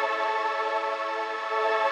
SaS_MovingPad05_125-A.wav